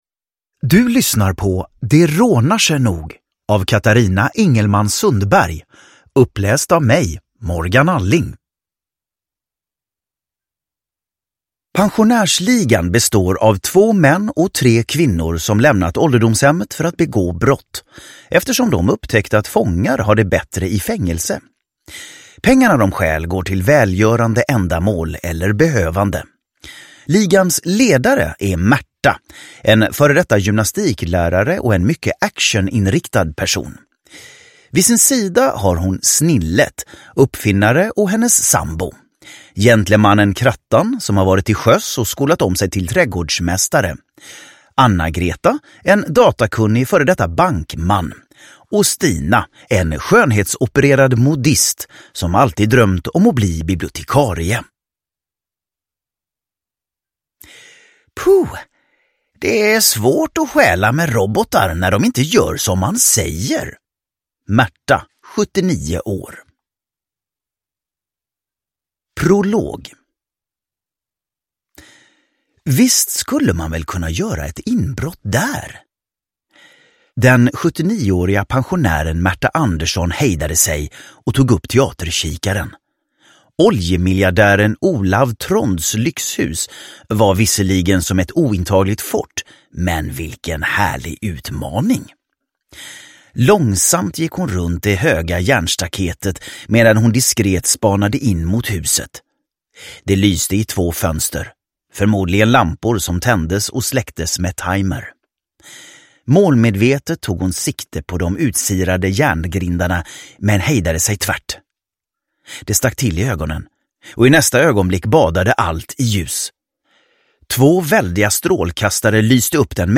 Det rånar sig nog – Ljudbok
Uppläsare: Morgan Alling